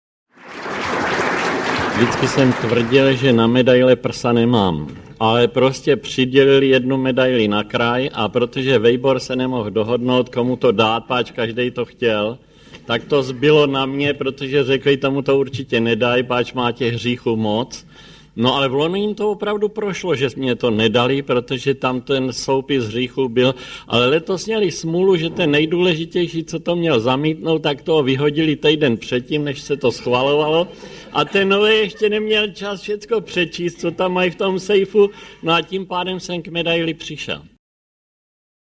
Většinou velmi veselé vyprávění známého malíře,jak ho zachytily mikrofony na besedě ve Frýdku-Místku v roce 1989, v Lyře Pragensis v roce 1998 a doma v Nedvězí v roce 2000.